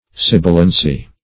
Meaning of sibilancy. sibilancy synonyms, pronunciation, spelling and more from Free Dictionary.
Search Result for " sibilancy" : The Collaborative International Dictionary of English v.0.48: Sibilance \Sib"i*lance\, Sibilancy \Sib"i*lan*cy\, n. The quality or state of being sibilant; sibilation.